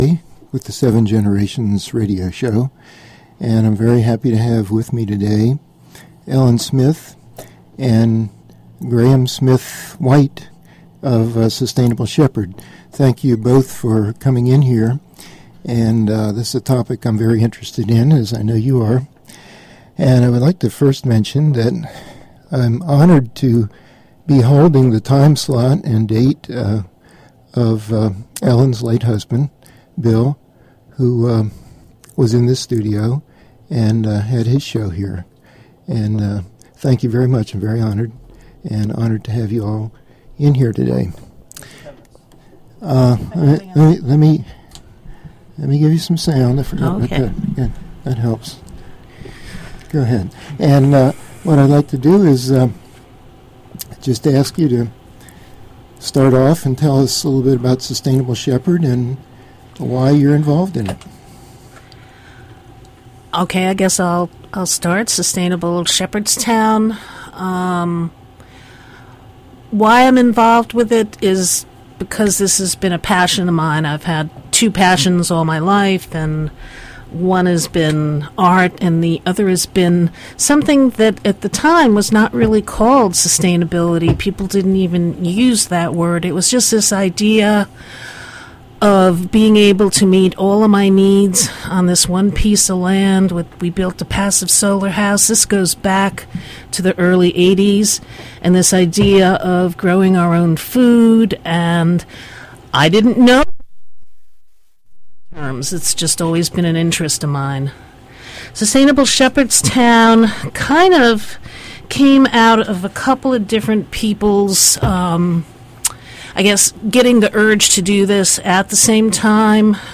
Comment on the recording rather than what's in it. Recorded at WSHC Radio, Shepherdstown, WV–7 Generations Show